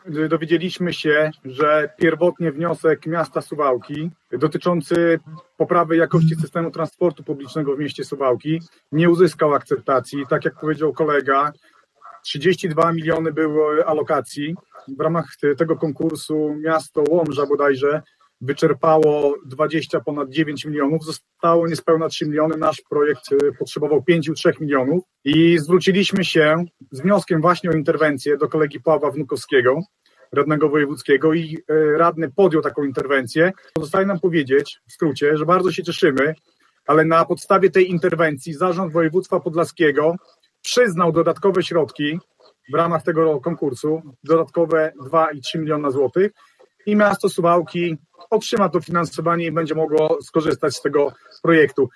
O swoim wniosku rajcy Jacek Juszkiewicz z klubu Prawo i Sprawiedliwość oraz Sławomir Sieczkowski z ugrupowania Mieszkańcy Suwałk, mówili w czwartek (30.09) podczas sesji.